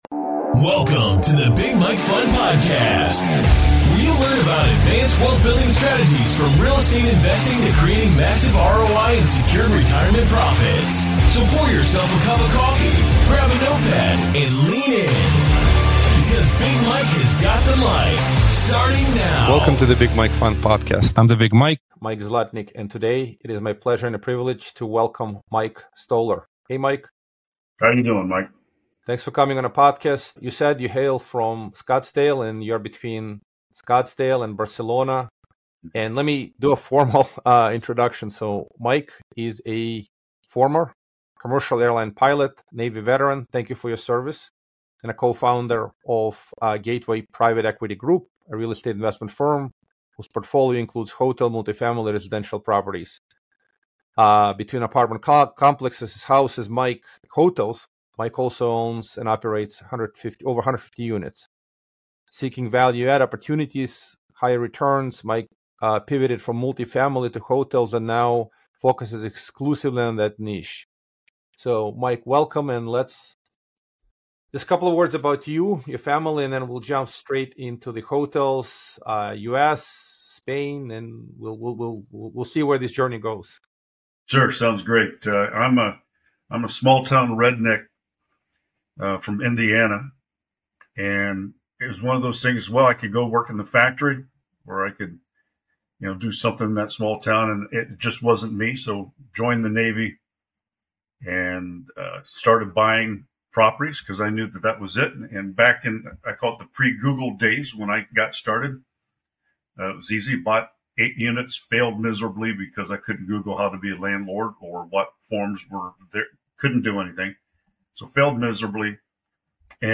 a no-holds-barred chat